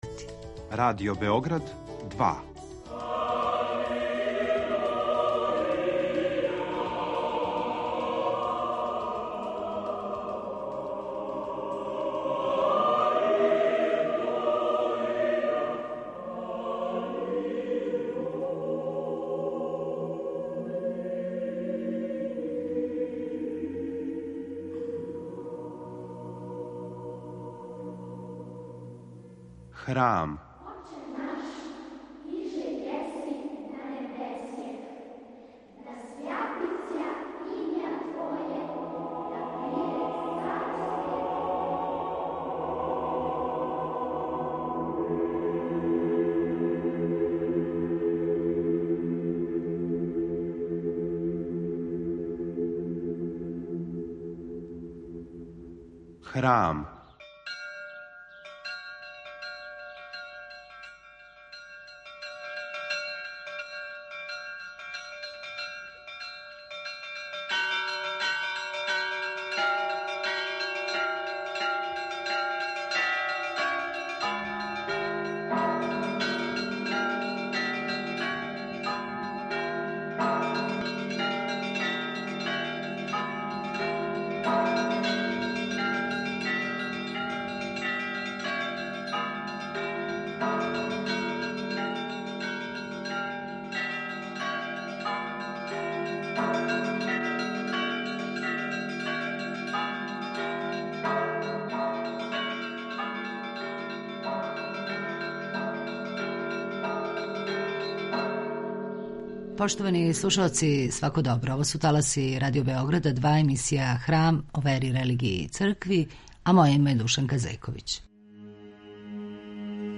У овонедељној емисији Храм говоримо о историји хумске епископије у средњем веку, а повод је објављивање, с благословом Његовог Преосвештенства Епископа захумско-херцеговачког и приморског г. Димитрија, II тома монографије, од предвиђених пет књига - Поводом 800 година од утемељивања: Историја Епархије захумско-херцеговачке и приморске. Гост у студију је